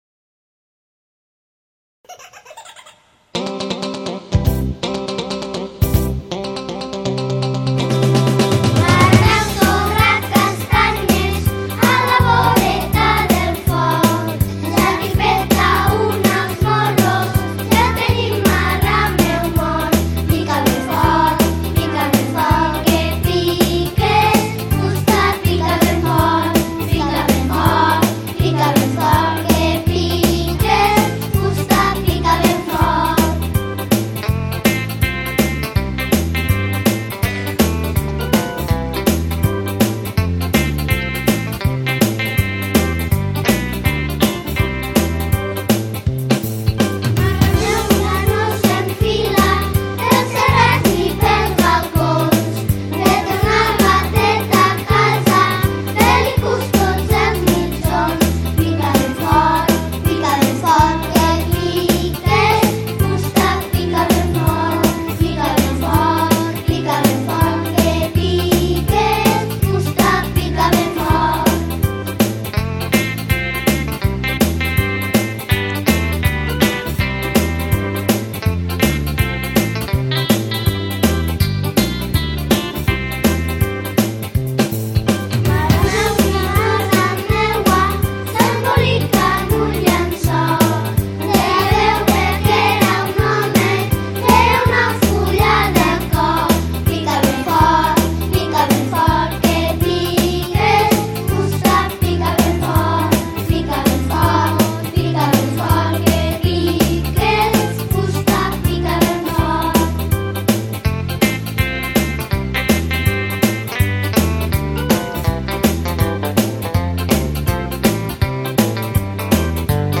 Les cançons que us presento són algunes de les que es van cantar a Cicle Inicial.
A primer van cantar “Una plata d’enciam” i a segon, un “Marrameu torra castanyes” molt canyero.